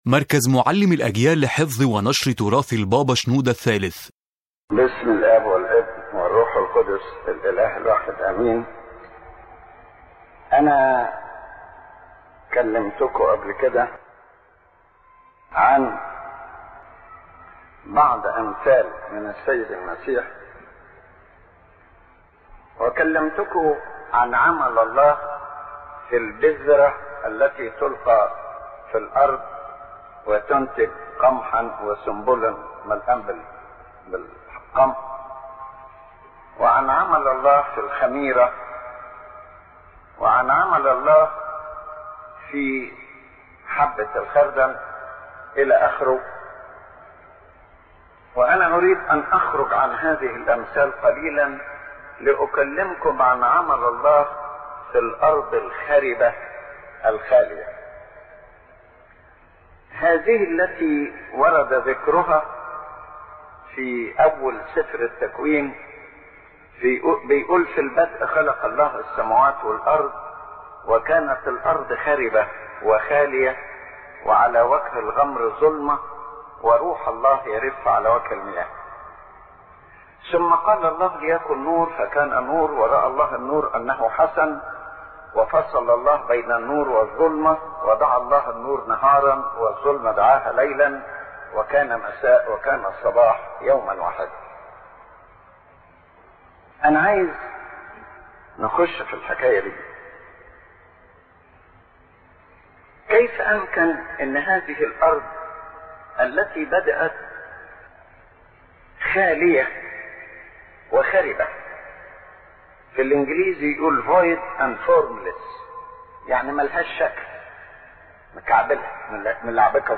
The General Idea of the Lecture